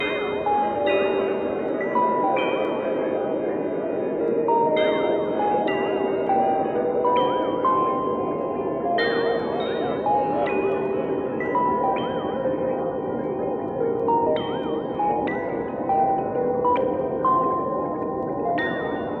• Space Ping Pong Robots Texture 100 bpm.wav
Space_Ping_Pong_Robots_Texture_100_bpm__riI.wav